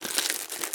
vending2.ogg